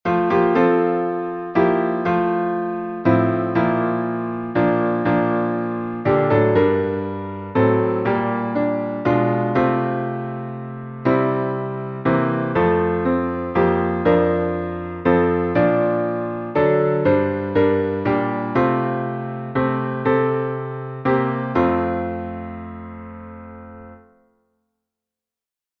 Salmo 36B Melodia: Manoah Métrica: 9 8. 7 8 Compositor: Gioacchino Rossini, 1851 Metrificação: Comissão Brasileira de Salmodia, 2015 1 1 Há no coração do pecador O clamor da transgressão.
salmo_36B_instrumental.mp3